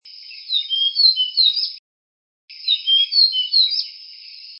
2-3柴山繡眼畫眉may30-1.mp3
繡眼雀鶥 Alcippe morrisonia morrisonia
高雄市 鼓山區 柴山
雜木林
單隻雄鳥歌聲
Sennheiser 型號 ME 67